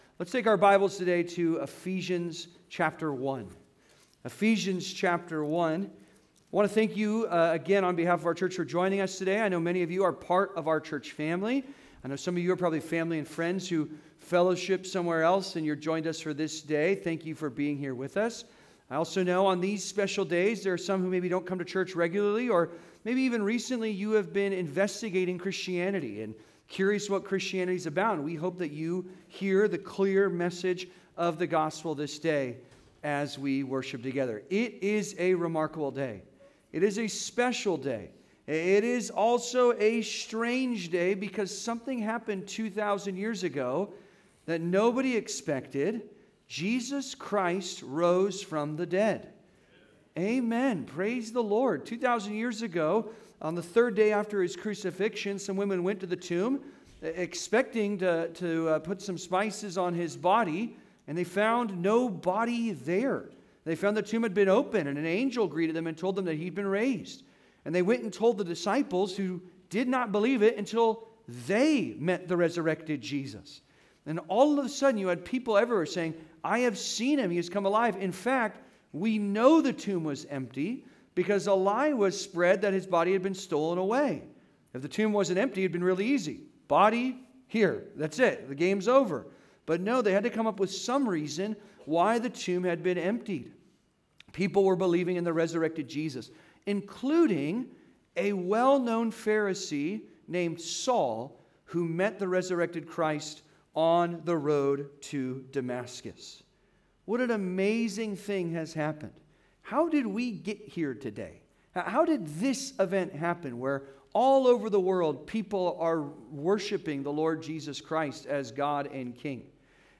Everyone Needs a Resurrection (Sermon) - Compass Bible Church Long Beach